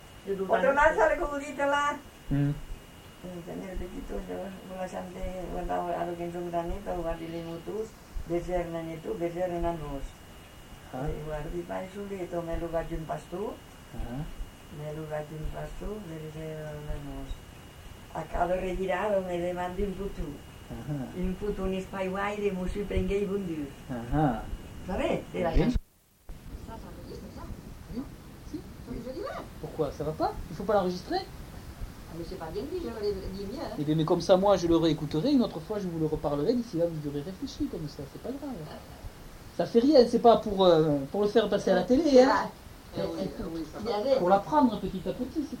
Aire culturelle : Couserans
Lieu : Ayet (lieu-dit)
Genre : chant
Effectif : 1
Type de voix : voix de femme
Production du son : récité